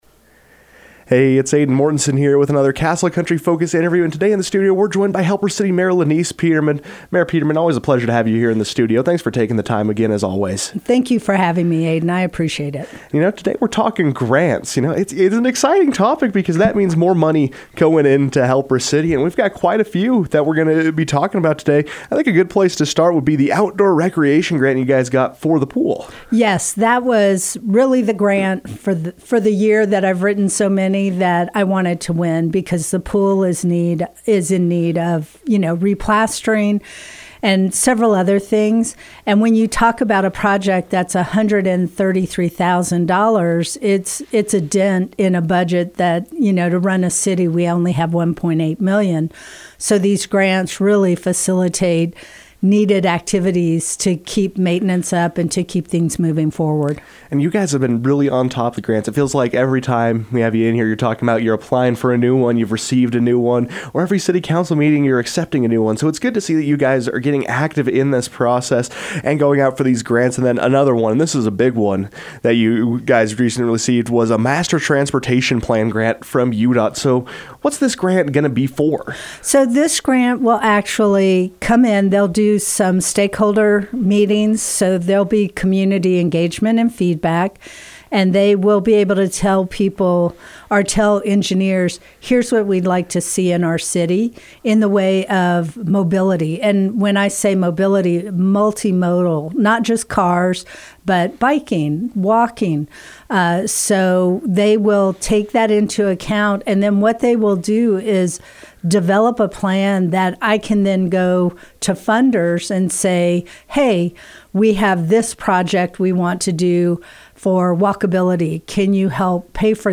Helper City Mayor Lenise Peterman joined the KOAL newsroom to explain the purpose behind these grants and provide an in-depth look into how the application process functions.